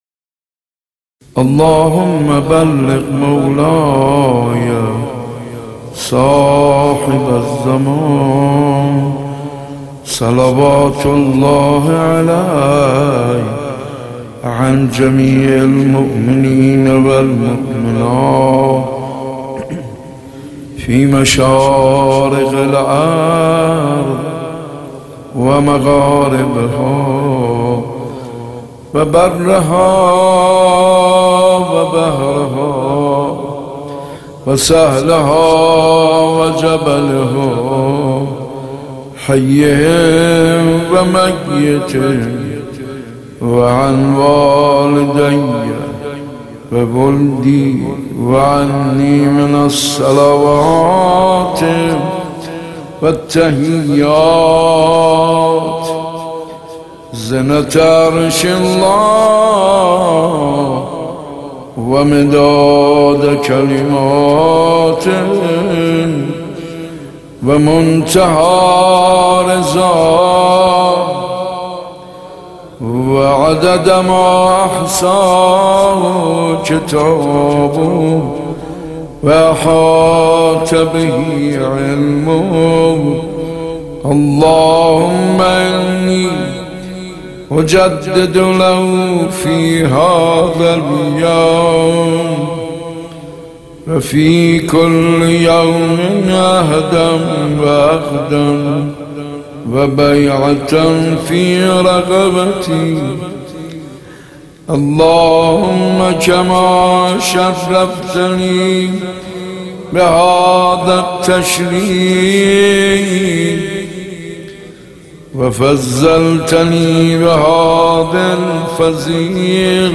حاج منصور ارضی/مناجات با امام زمان(عج)